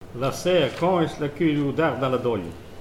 témoignages
Catégorie Locution